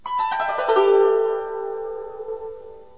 snd_ui_dakai2.wav